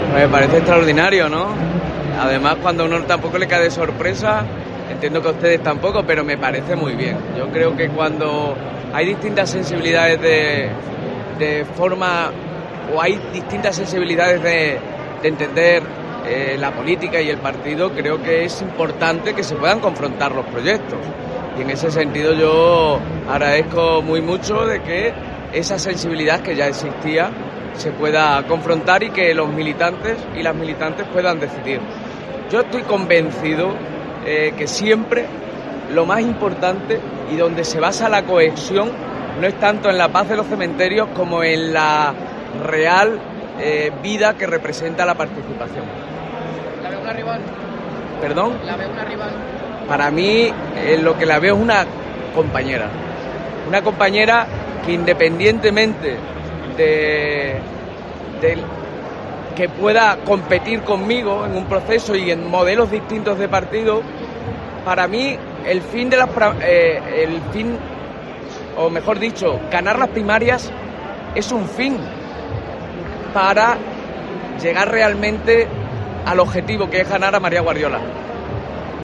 En este sentido, y a preguntas de los medios con motivo de su presencia en Fitur, ha considerado "extraordinario" que Garlito dé el paso para optar a la Secretaría General de los socialistas extremeños.